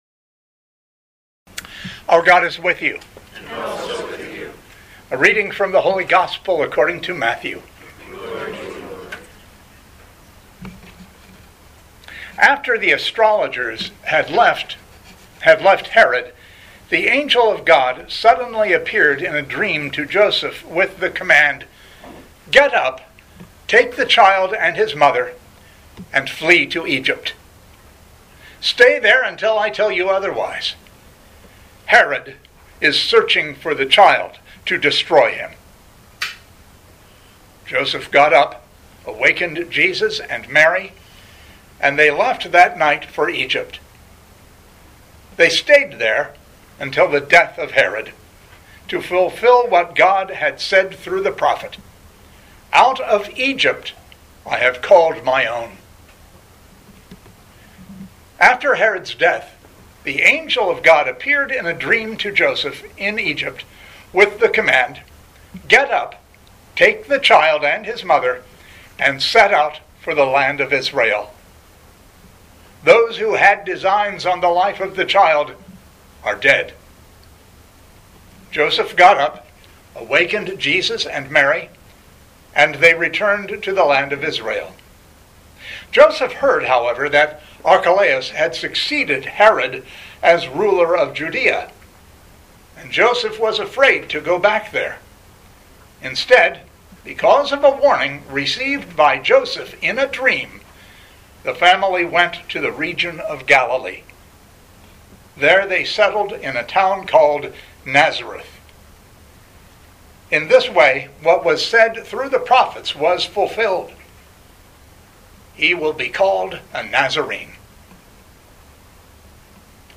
Living Beatitudes Community Homilies: Holy Family Holy Gifts